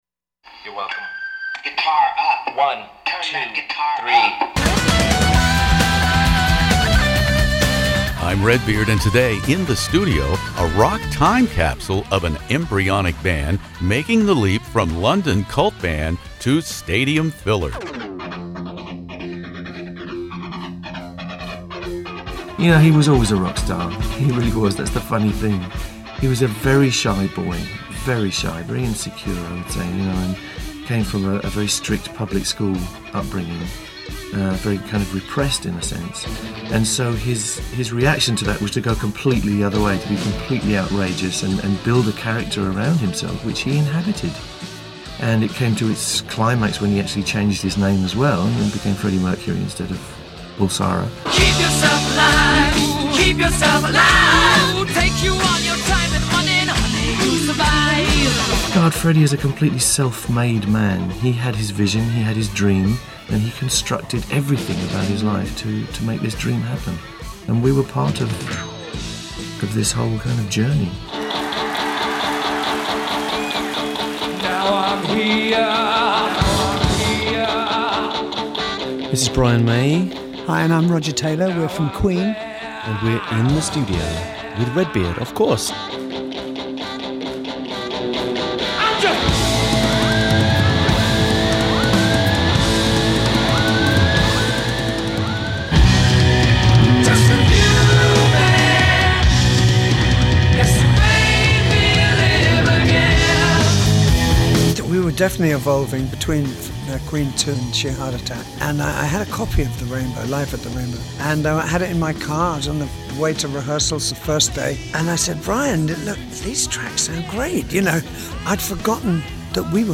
We are celebrating an historic milestone in rock history: the fiftieth anniversary of the release of Queen’s A Night at the Opera album! To mark the occasion, Brian May and Roger Taylor of Queen join me here In the Studio to recall the early recordings and London club days with the embryonic quartet, on its way to rewriting the rock record books.